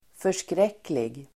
Uttal: [för_skr'ek:lig]